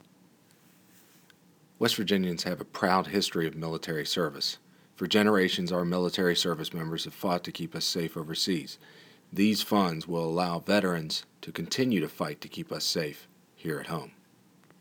Click here to listen to an audio clip from U.S. Attorney Goodwin regarding today’s funding announcement.
Goodwin-COPS-funding.wav